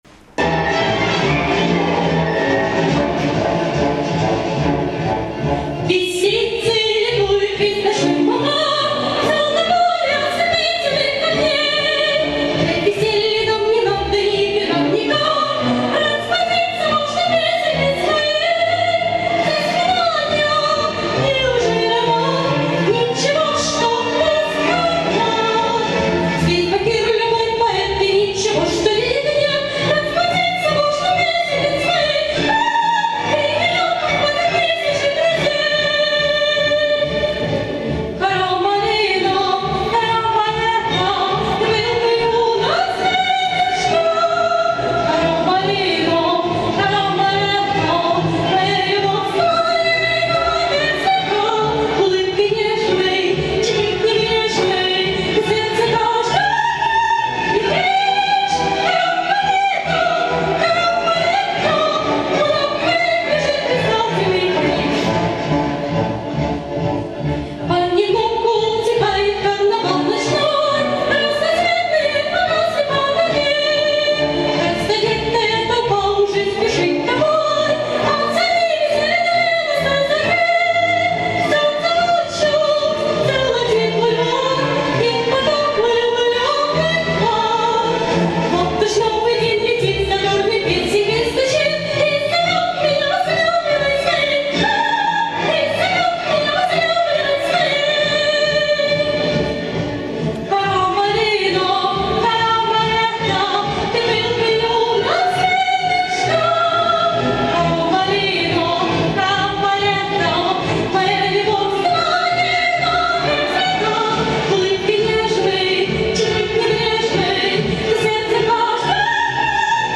Живое звучание